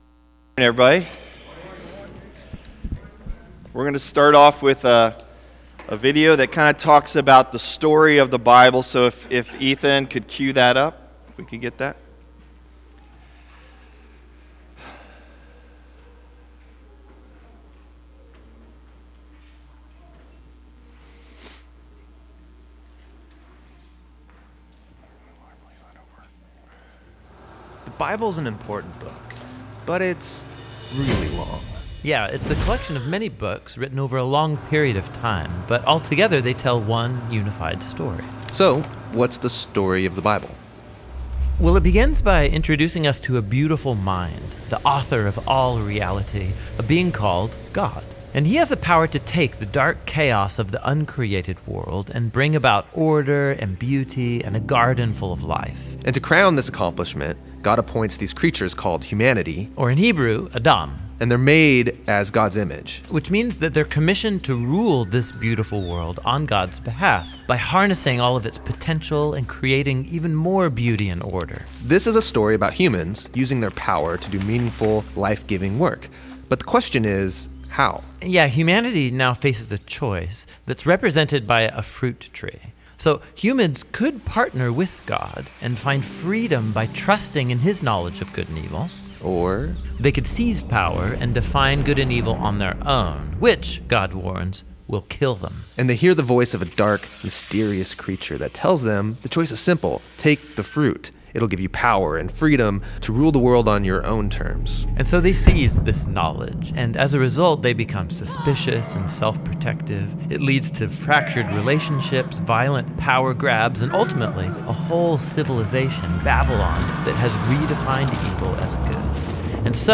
June 25 Sermon | A People For God